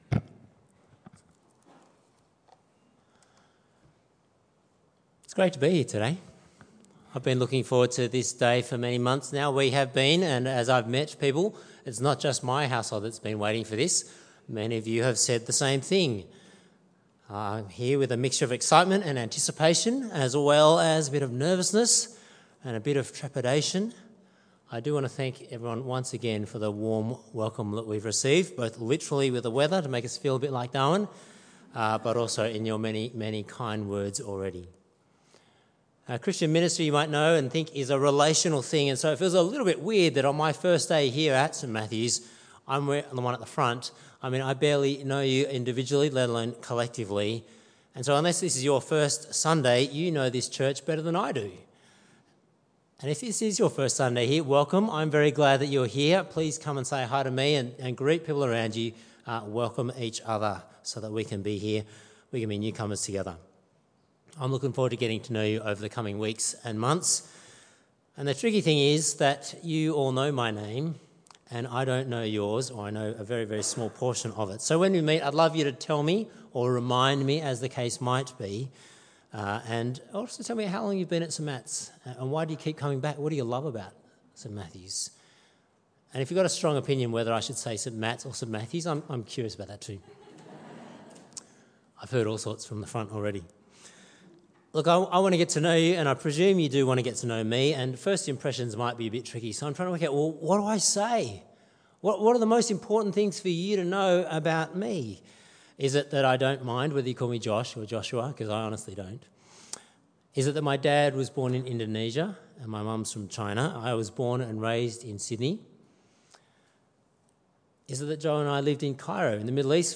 Sermon Series | St Matthew's Wanniassa